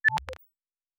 pgs/Assets/Audio/Sci-Fi Sounds/Interface/Data 23.wav at master